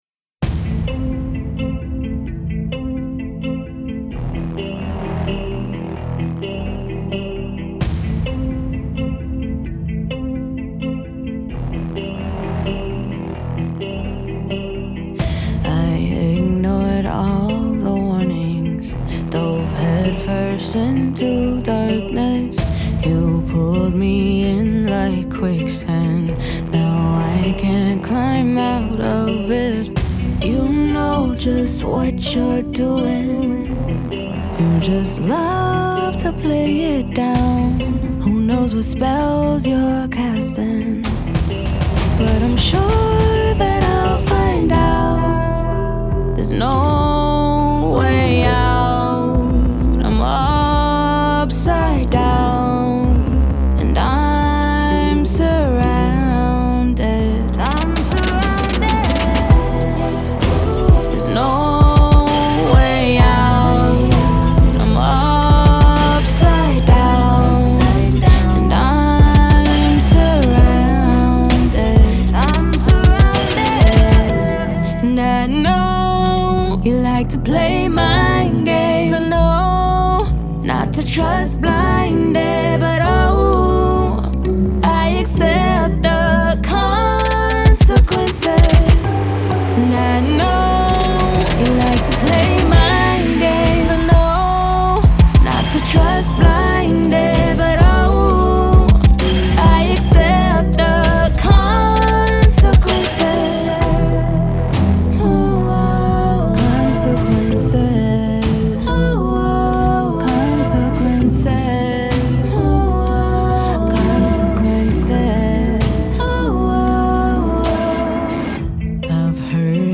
Pop, R&B
E Major